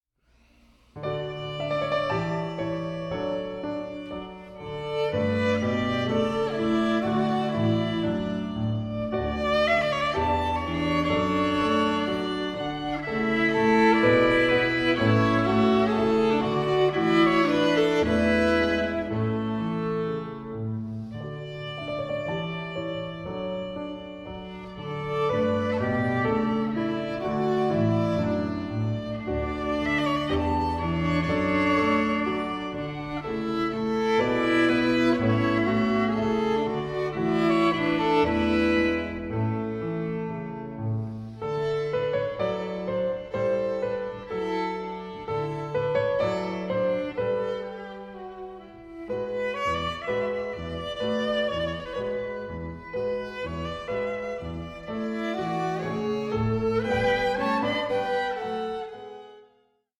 for viola and piano